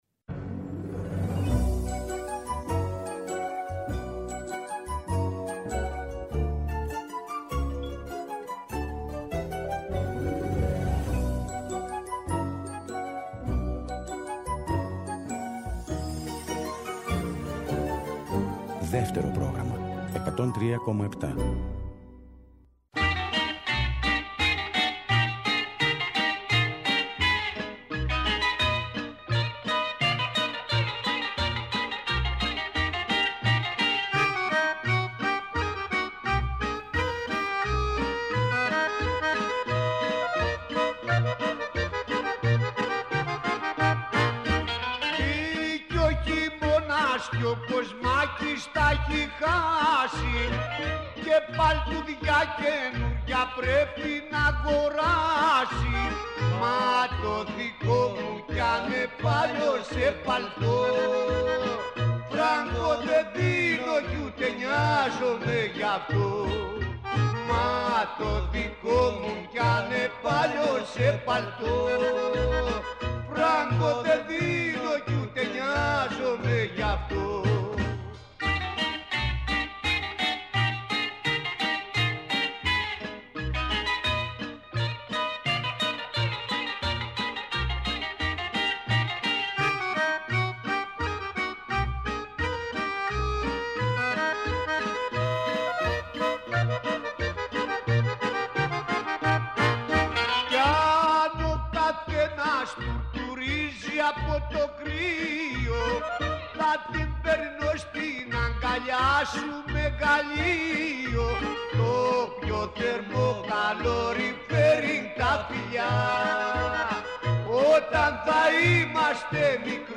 Πολυσυλλεκτική ραδιοφωνική περιπλάνηση με τραγούδια και γεγονότα.